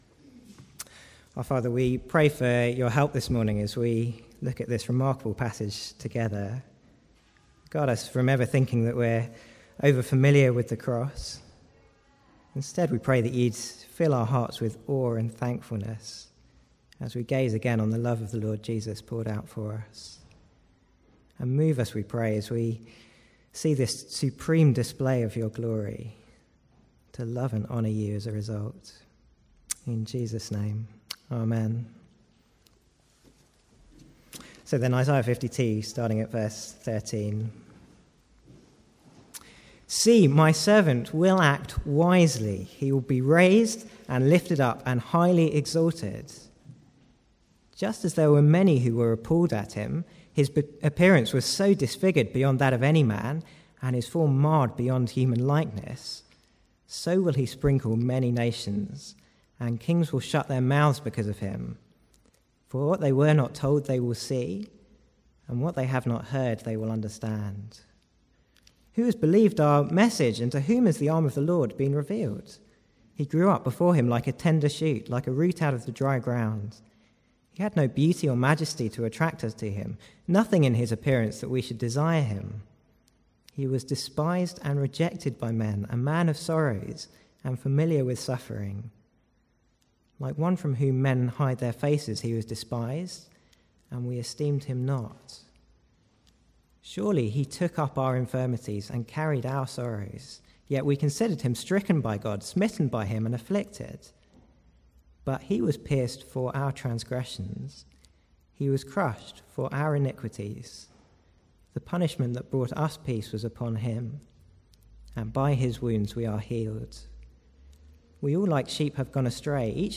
From the Sunday morning series in the Servant Songs of Isaiah.
Sermon Notes